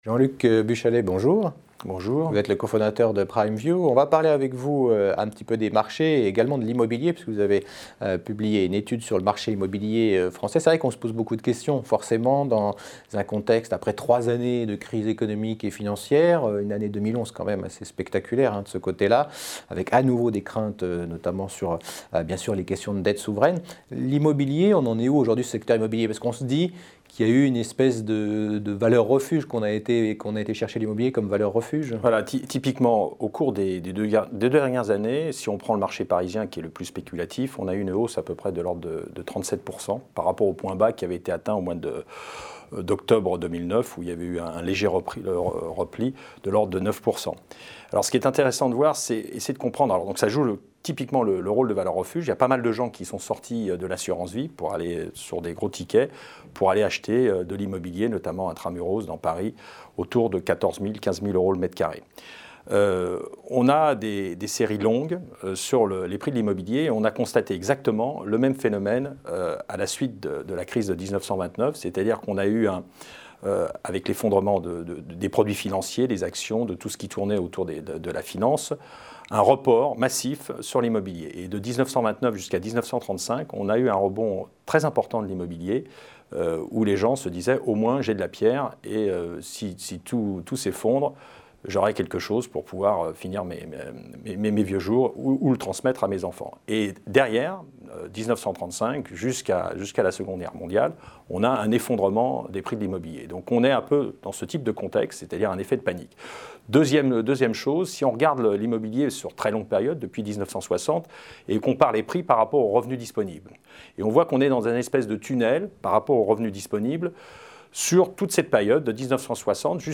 Immobilier : Interview de